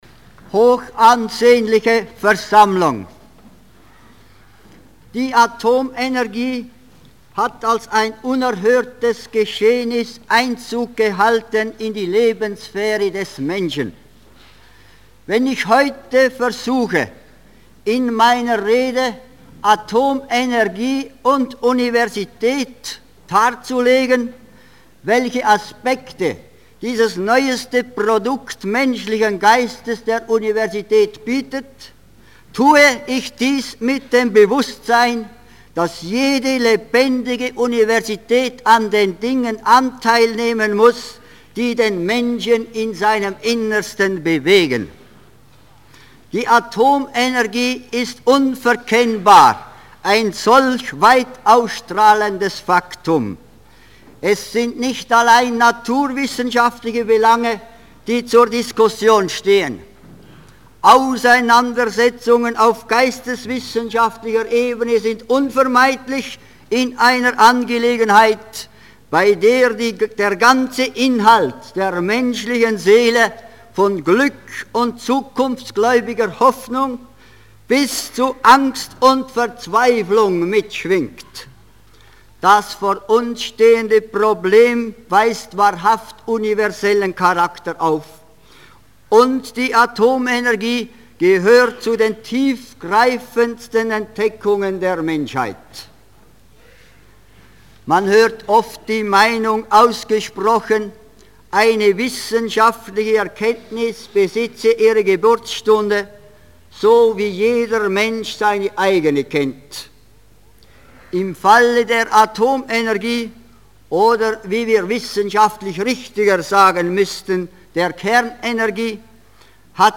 Rektoratsrede 1958